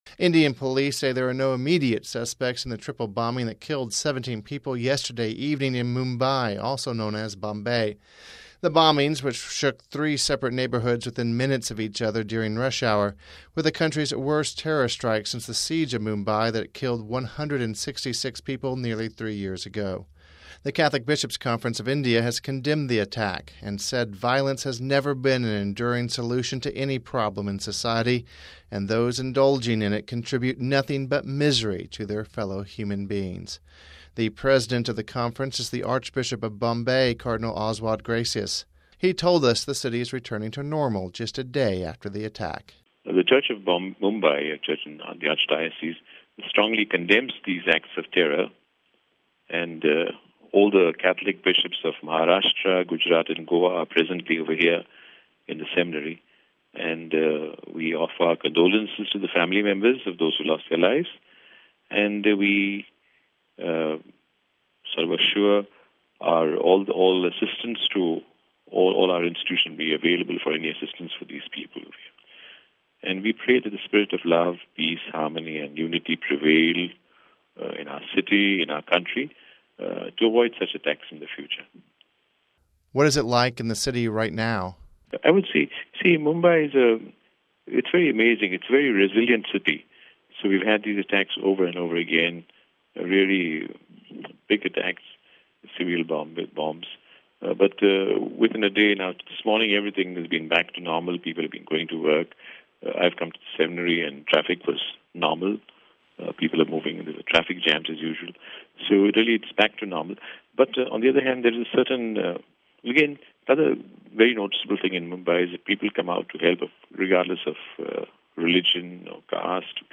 The President of the Bishops’ Conference, the Archbishop of Bombay (Mumbai), Cardinal Oswald Gracias told Vatican Radio the people of the city have quickly rebounded from the attack.